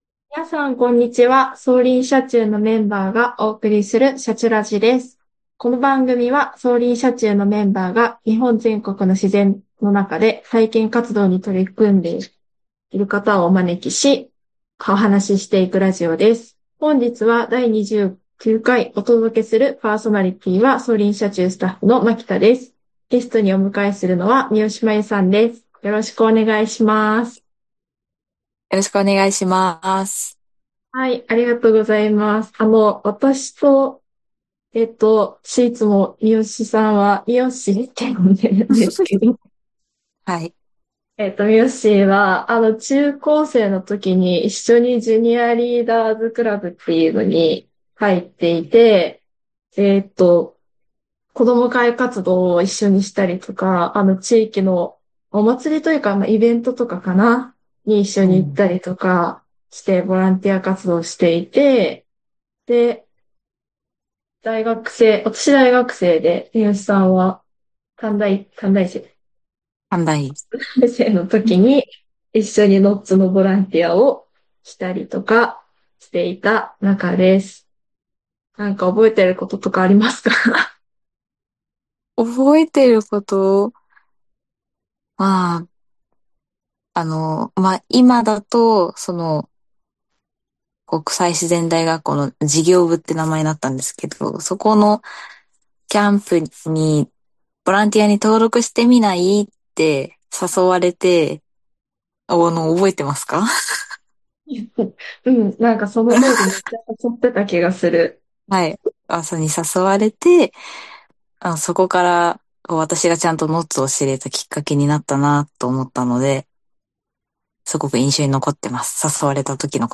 「シャチュラジ」この番組は、走林社中のメンバーが日本全国で自然の中での体験活動に取り組んでいる方々をゲストにお招きし、お話ししていくラジオです。